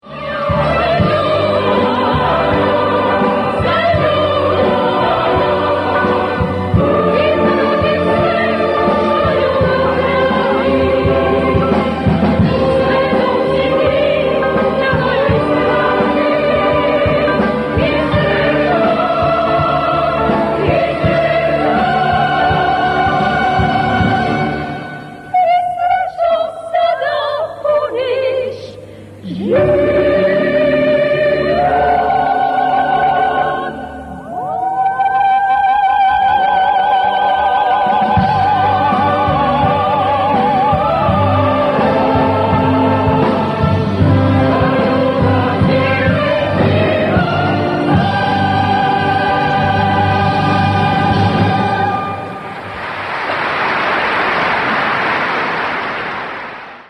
mjuzikl
Radna snimka s koncerta, zvuk slabije kvalitete.